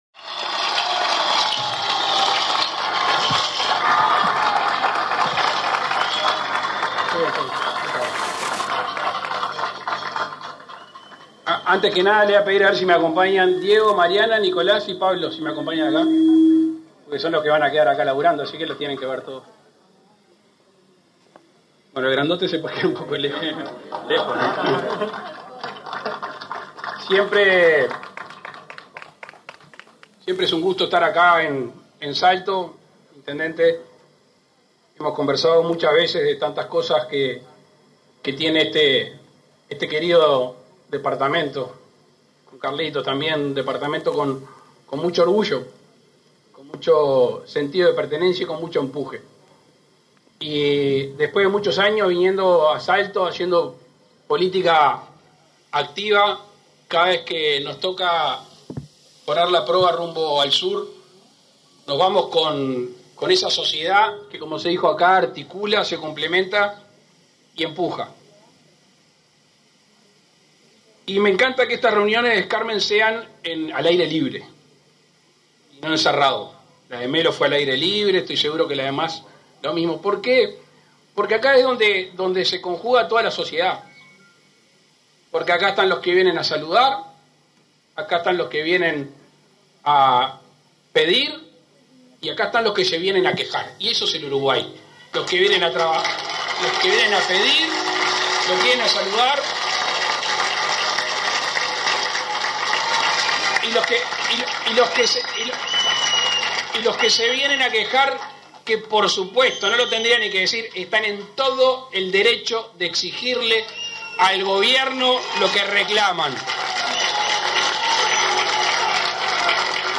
Palabras del presidente de la República, Luis Lacalle Pou
El presidente Lacalle Pou participó este 30 de setiembre en la inauguración del Centro Pyme de Salto, donde además funcionarán oficinas de la Comisión